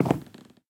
Minecraft Version Minecraft Version snapshot Latest Release | Latest Snapshot snapshot / assets / minecraft / sounds / block / dried_ghast / step / wood5.ogg Compare With Compare With Latest Release | Latest Snapshot
wood5.ogg